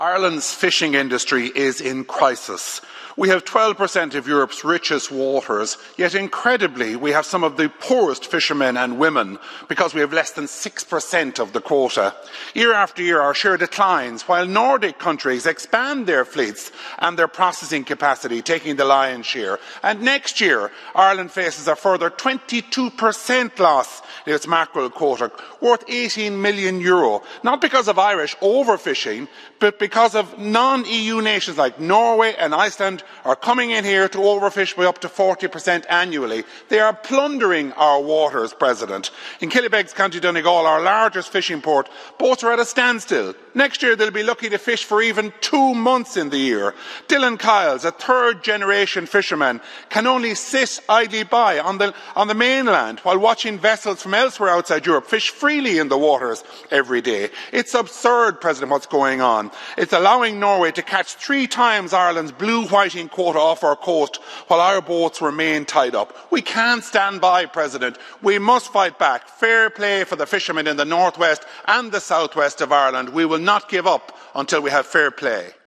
Speaking in the European Parliament, Ciaran Mulloley said unfair quota allocations and overfishing by non-EU nations are crippling Irish fishermen, forcing boats to remain tied up while foreign vessels exploit Irish waters.